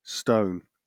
amer. IPA/stoʊn/
wymowa amerykańska?/i